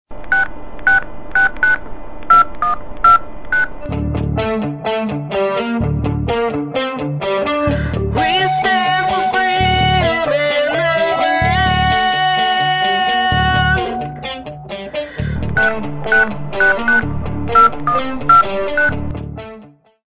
Storm' riff